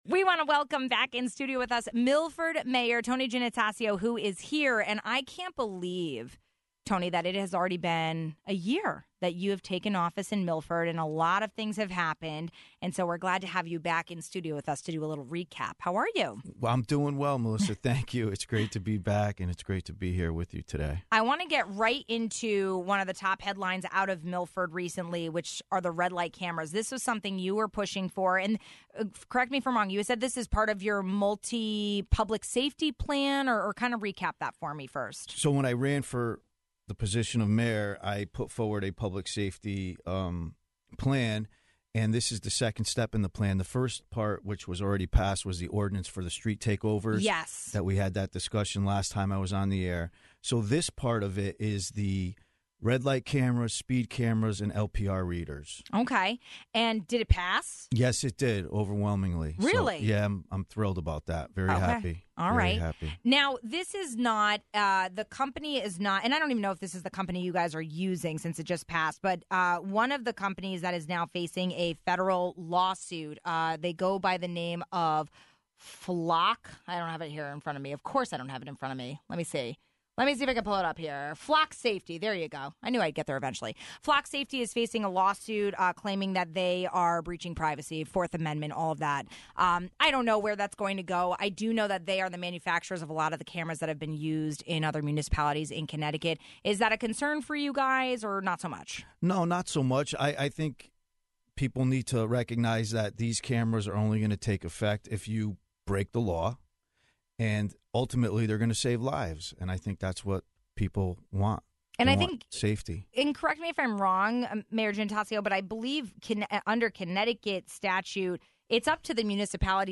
Milford Mayor Tony Giannattasio stopped in studio to talk about the approval of red-light cameras in the city and a look back at his first year in office.